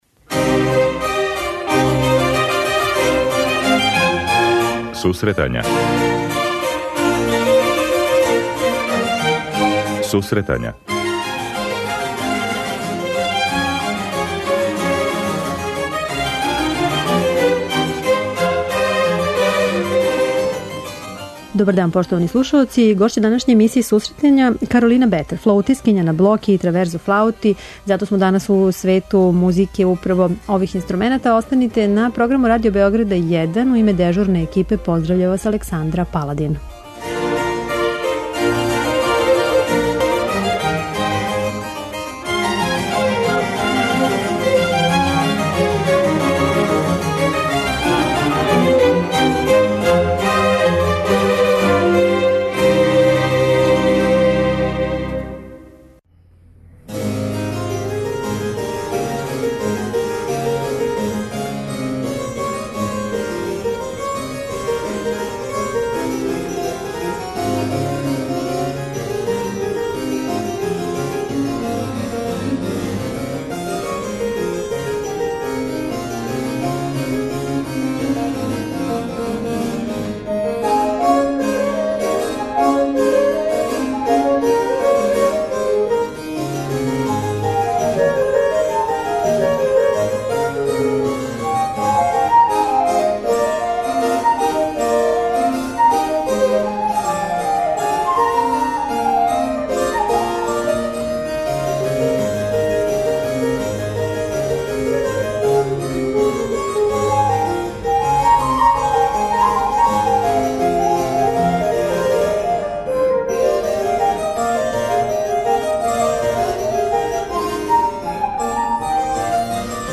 Данас ћемо бити у свету музике за старе инструменте.